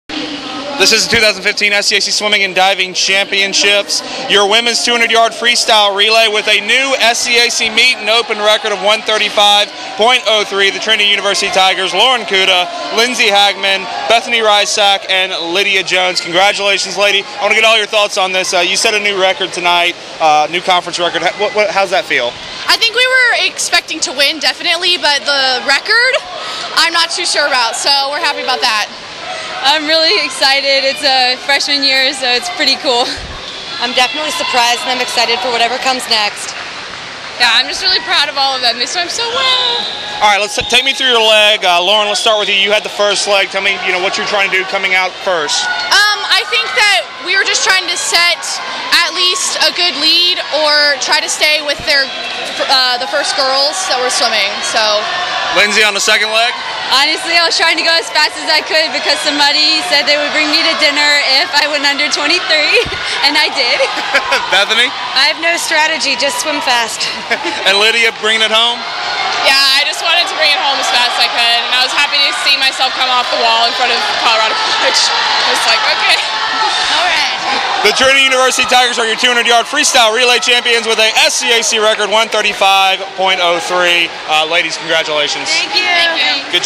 interview   finals | photo interview